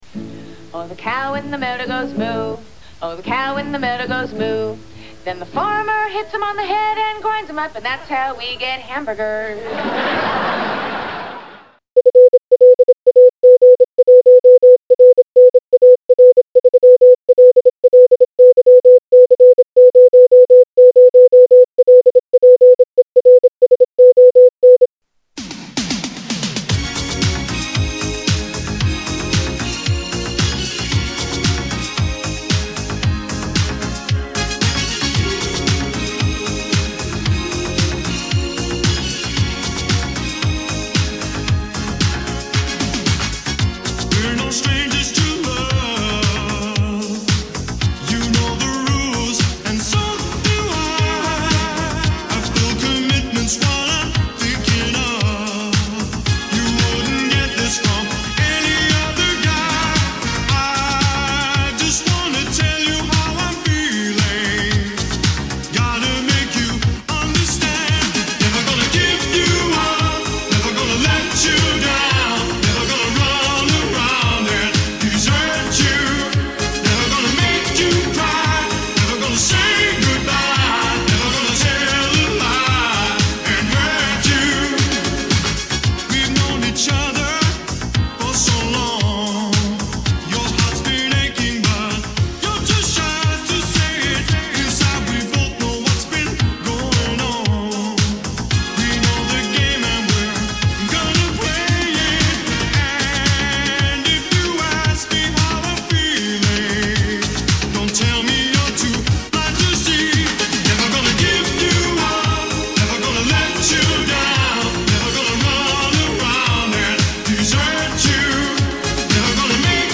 Hmmm….a song at the start, and then beeping sounds? Oh wait a sec…isn’t that Morse Code?
There is more audio even after the morse code?
25w500f means 25 words per min 500 frequency.